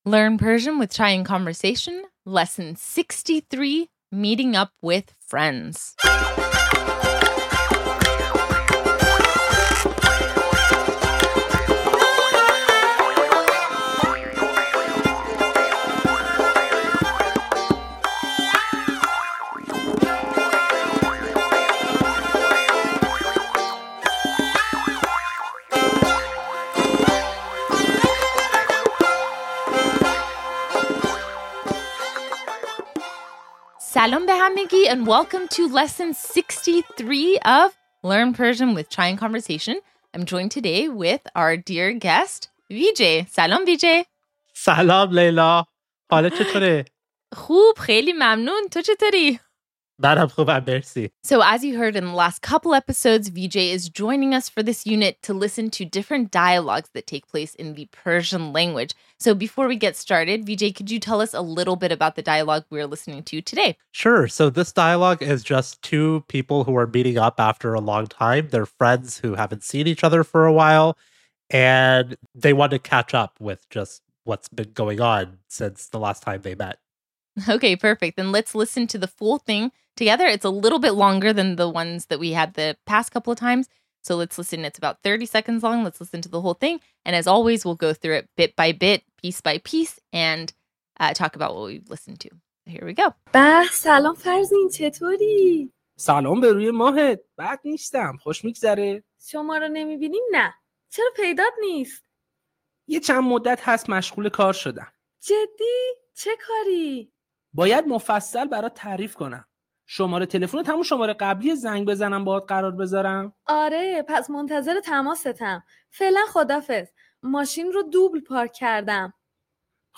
Lesson 63: Meeting Up with Friends - Learn Conversational Persian (Farsi)